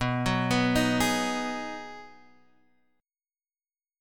B6 Chord